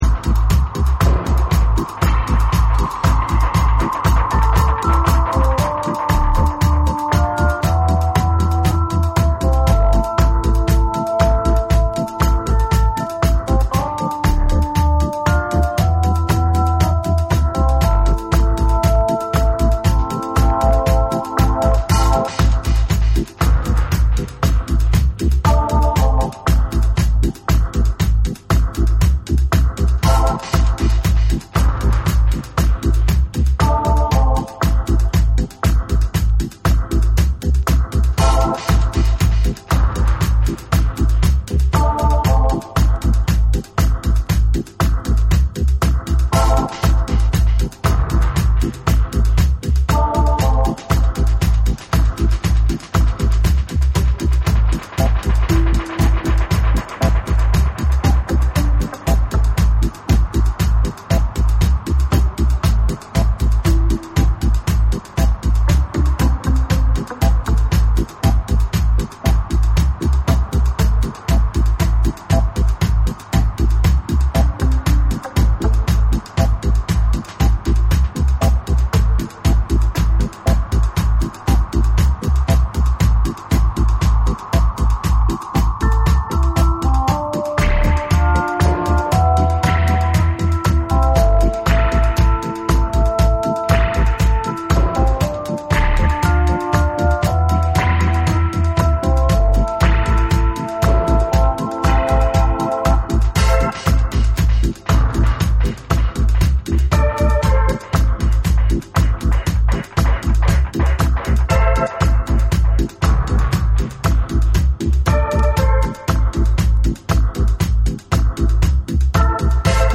ダビーなトラックに鍵盤のメロディーがディープに溶け込むミニマル・ダブ
JAPANESE / REGGAE & DUB